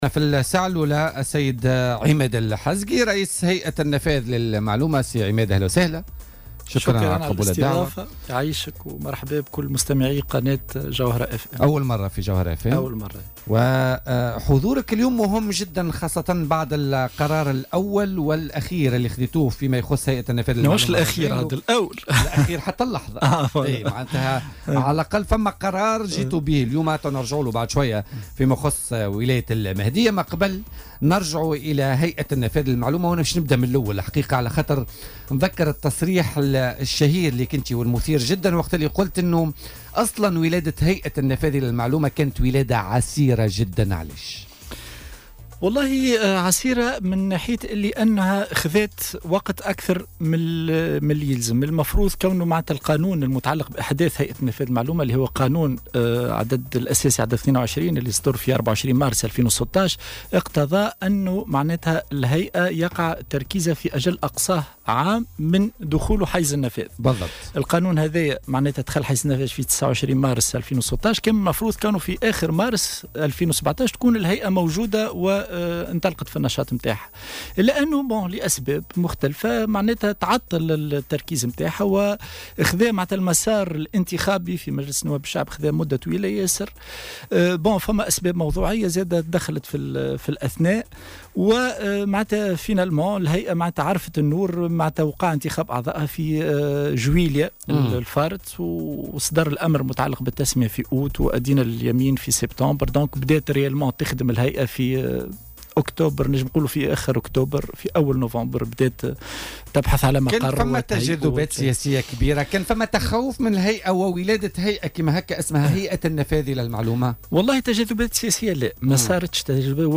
وأوضح ضيف "بوليتيكا" على "الجوهرة أف أم" أنه لا يمكن للهيئة حاليا الاعلان عن مناظرات خارجية للانتداب وستكتفي في الوقت الحالي بانتدابات عن طريق الإلحاق وهي بصدد البحث عن الكفاءات المناسبة للعمل صلب الهيئة في انتظار تعزيز طاقم الهيئة بكفاءات خارجية.